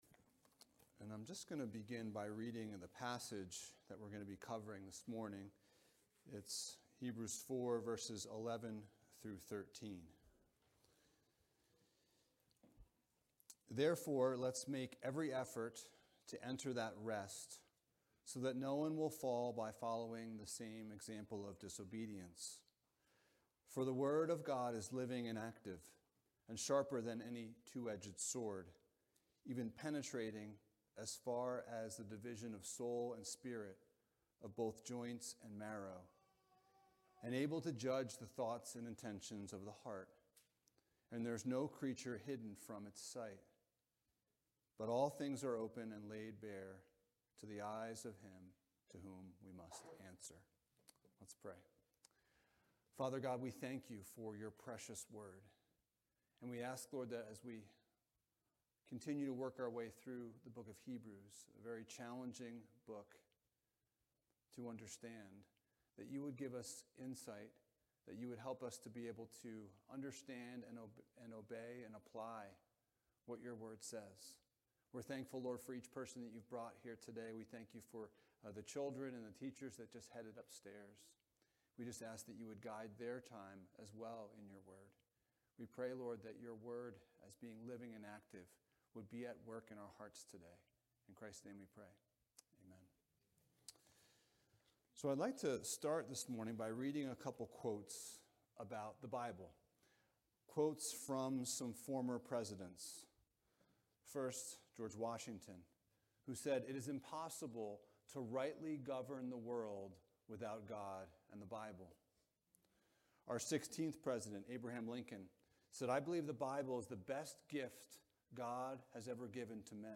Jesus is Better Passage: Hebrews 4:11-13 Service Type: Sunday Morning « Finding Rest Amidst Anxiety Jesus